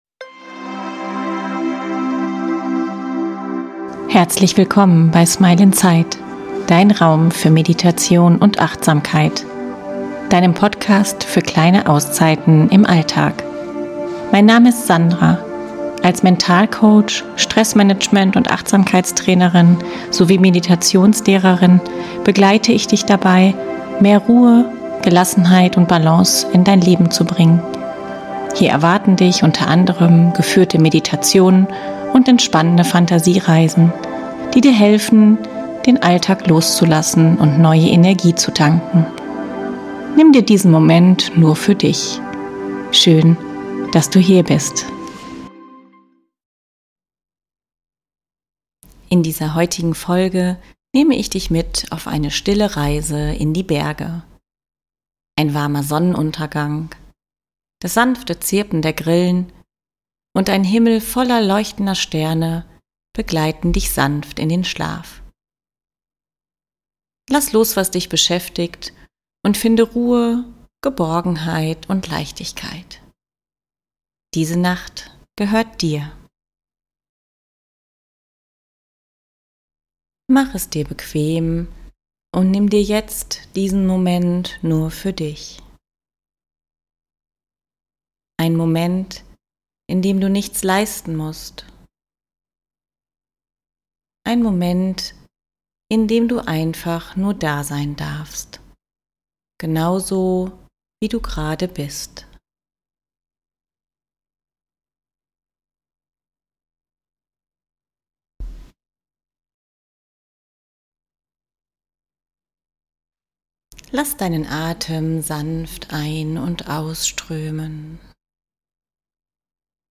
Die heutige Episode begleitet dich mit einem sanften Sonnenuntergang, dem beruhigenden Zirpen der Grillen und einem klaren Sternenhimmel in die Nacht.